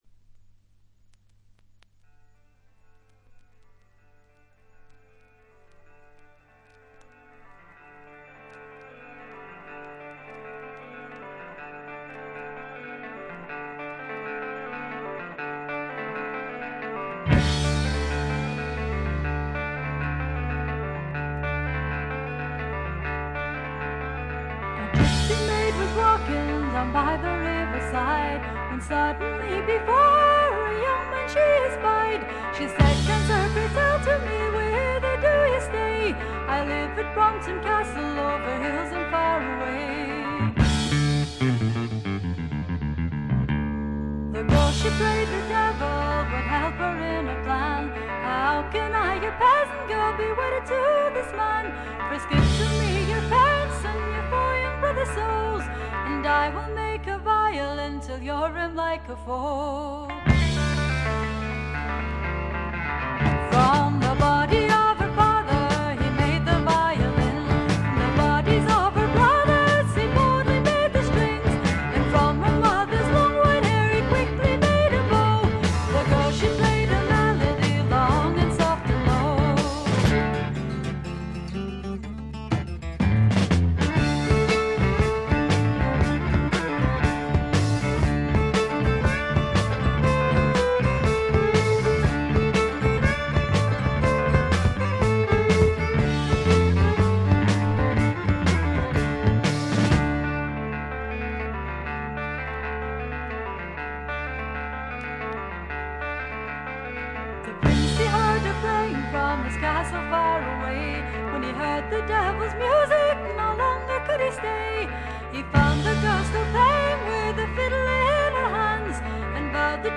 他は軽微なチリプチが少々。
試聴曲は現品からの取り込み音源です。